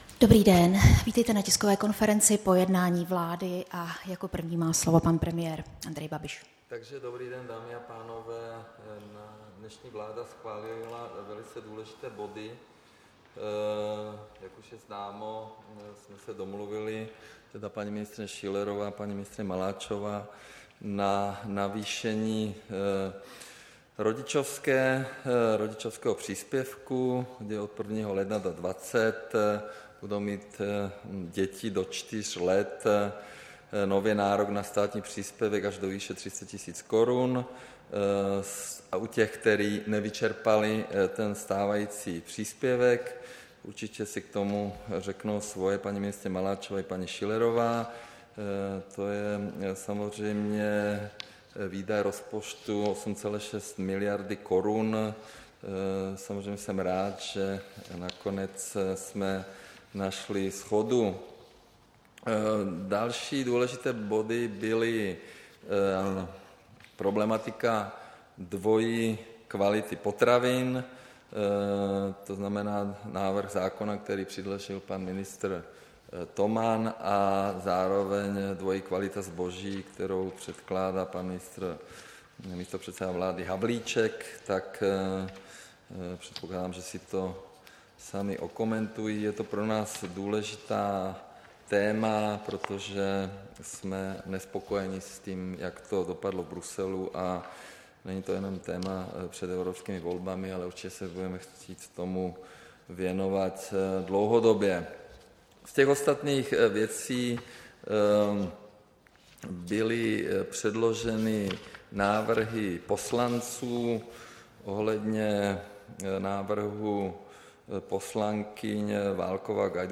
Tisková konference po jednání vlády, 20. května 2019